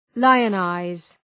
Shkrimi fonetik {‘laıə,naız}